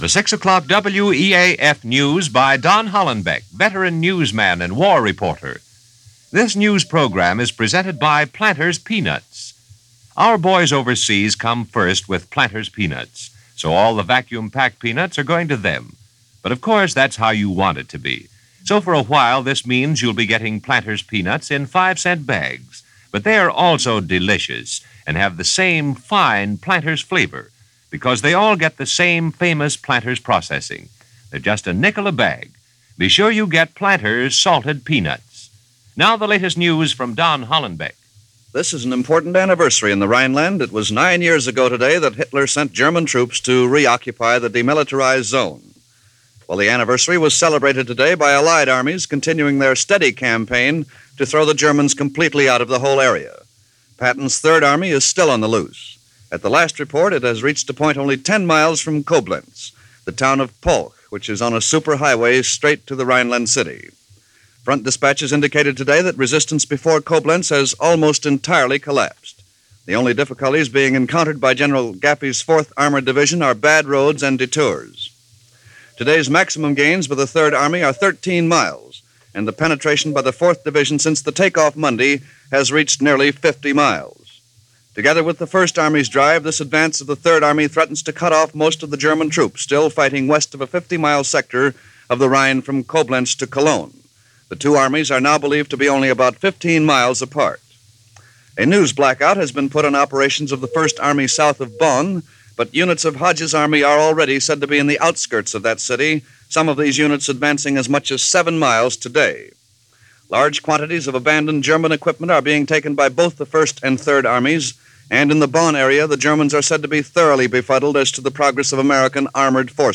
Allied Armies In The Rhineland - Resistance Collapsing - Advance 50 Miles - March 7, 1945 - 6:00 news from WEAF-New York.